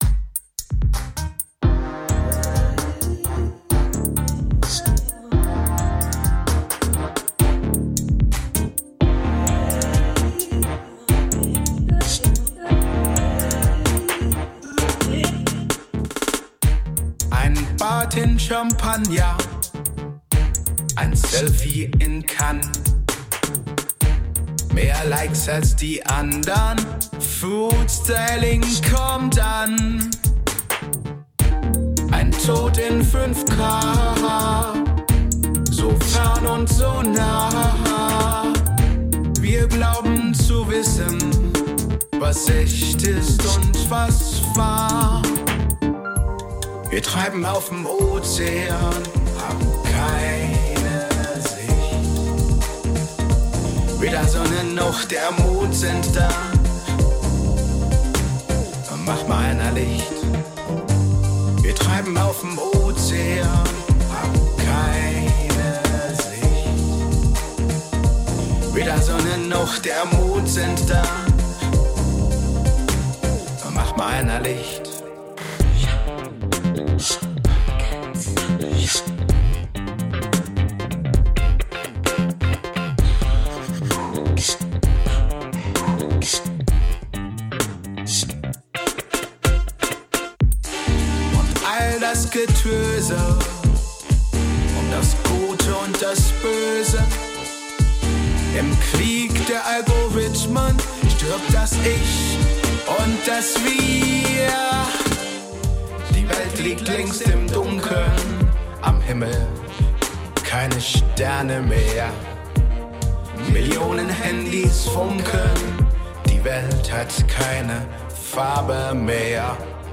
Hab dann doch acht Instrumental-Takte nach den ersten Refrains reingesetzt.